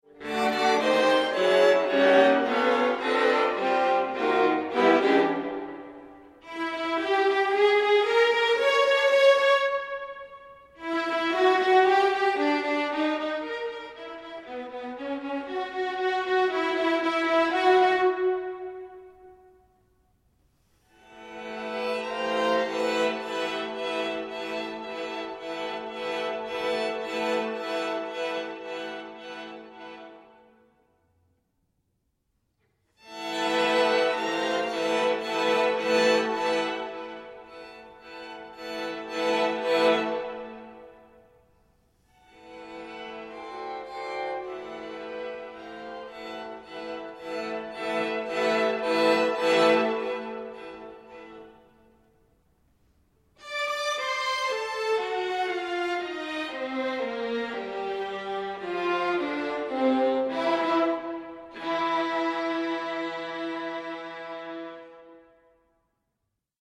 violin
viola
alto and soprano saxophones
mandola, Spanish guitar
piano